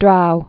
(drou)